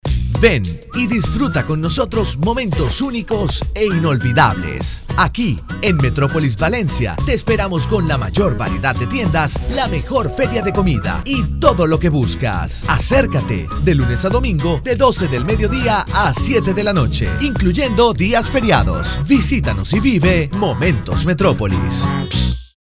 Sprechprobe: eLearning (Muttersprache):
Male Spanish voice over, latin voice, young voice, fresh voice, Español, voiceover, locutor, voice acting, dubbing actor, video corporativo, voz masculina, acento neutro, acento venezolano, warm, comforting, powerful, sincere, authentic, fun, relaxed, conversational, GENUINE, FRIENDLY